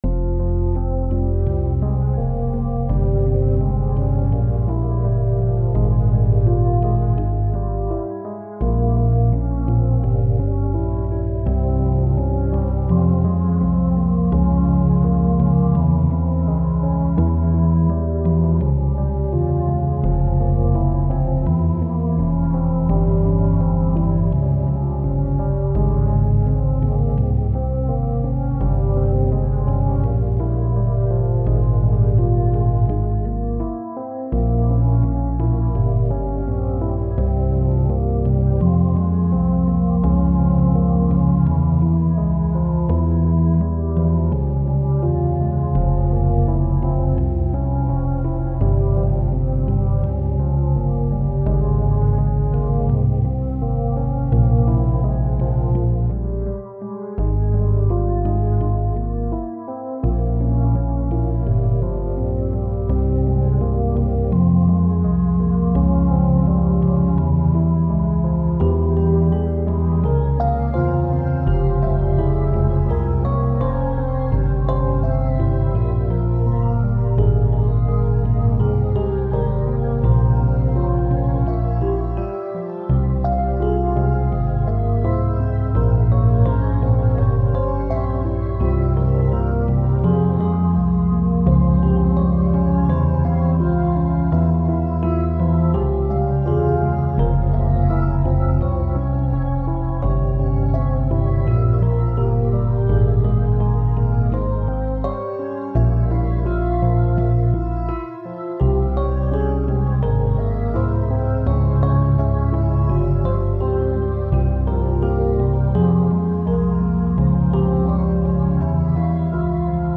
On repart sur une série "Fractale Piano".
Morse (10 - 10 ) Fa (F) Minor Harmon. 84
Plugins : MDA Piano, Organized trio, synth1